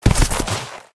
死亡音效
Camel_Mount_Death_Underlay.ogg